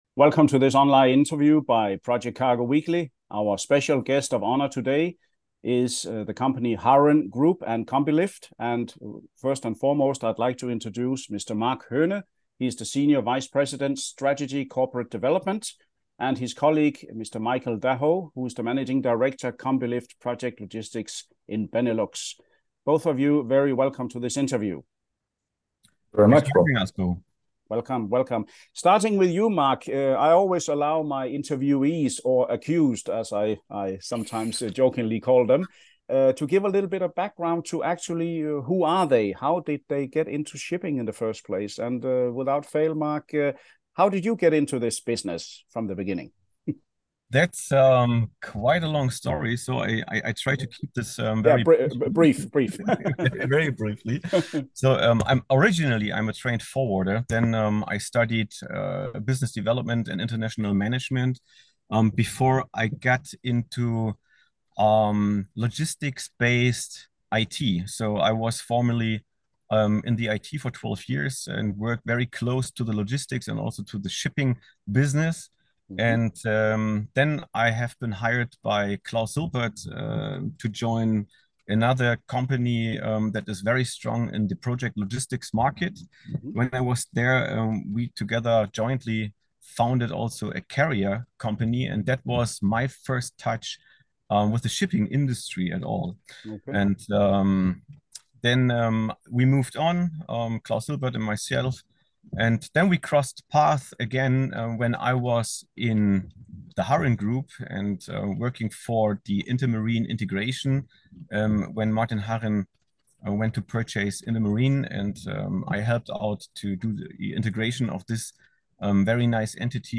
Video InterviewHarren Group / Combi Lift Project Logistics